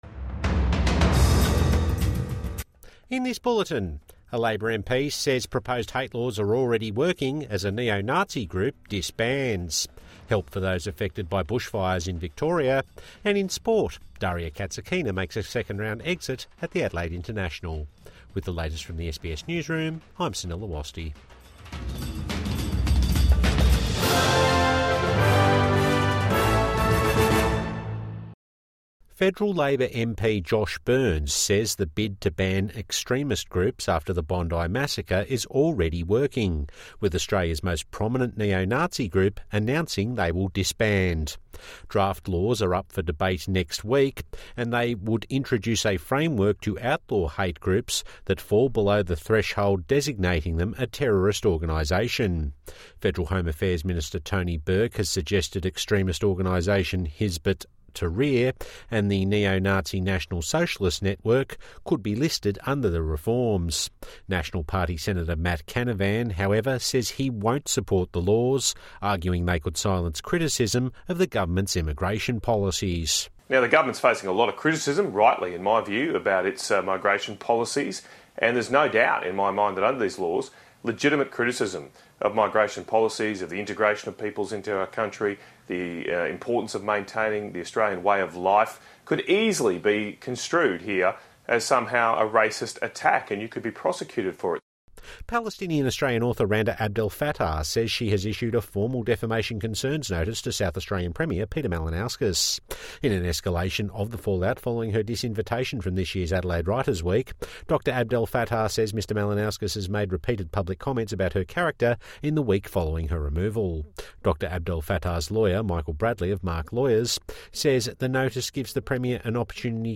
Proposed new laws already working, says Burns, as NSN disbands | Evening News Bulletin 14 January 2026